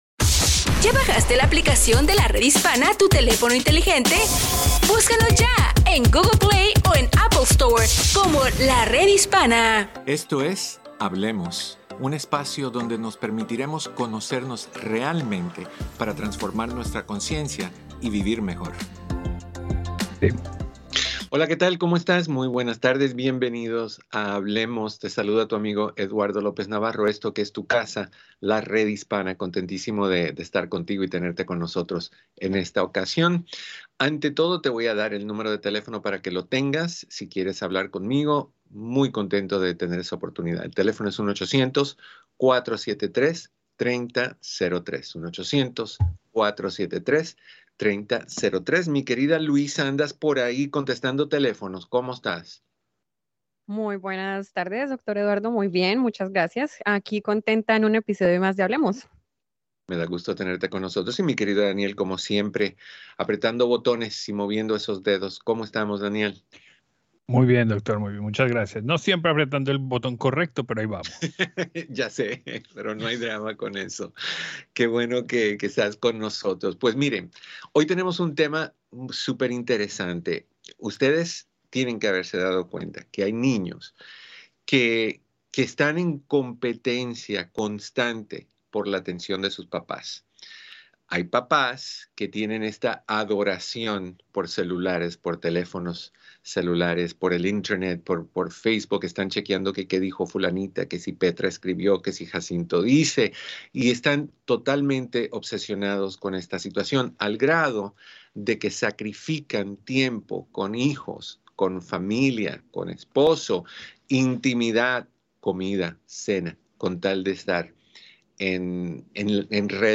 ¡Estamos en vivo!